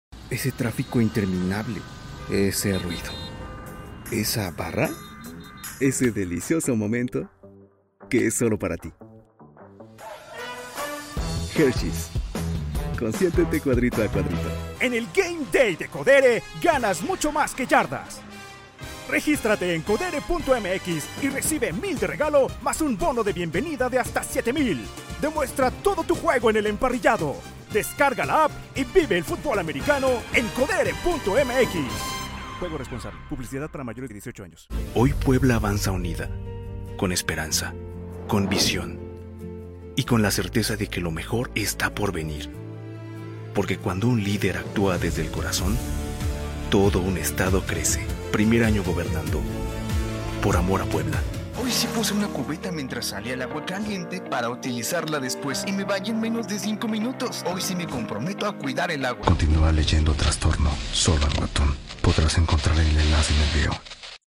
Voz en off para cursos E-learnig y publicidad, TV, radio, perifoneo, holds telefónicos, narración.
Locutor comercial - Voice over talent